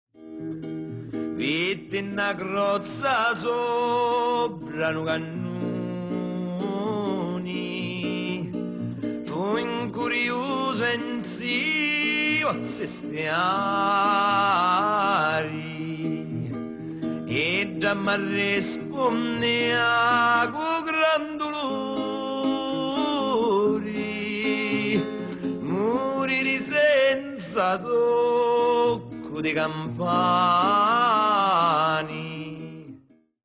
Melodie popolari
La musica popolare utilizza melodie semplici , facilmente memorizzabili.
Osserva e ascolta un frammento di Vitti 'na crozza , canto popolare siciliano: vitti_na_crozza.mp3